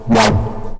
tekTTE63024acid-A.wav